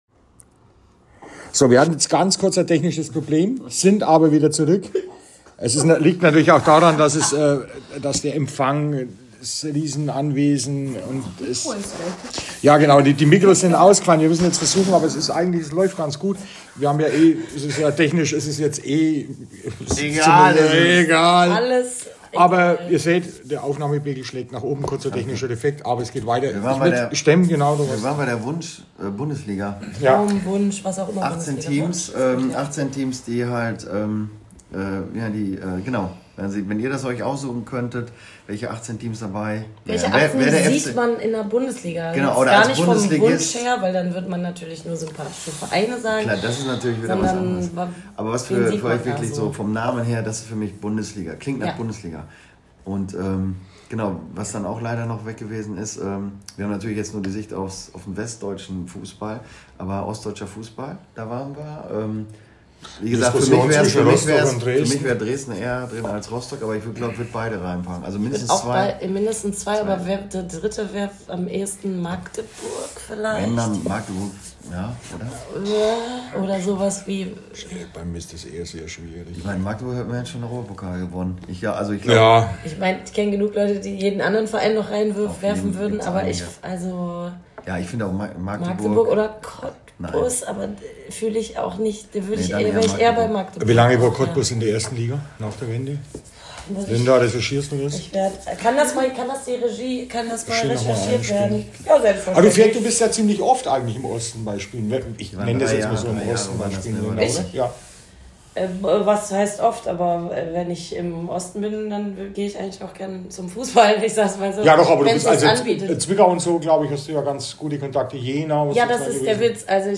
Es bleibt punkig und amateurhaft!:) Viel Spaß und bis zum nächsten Mal!:) Mehr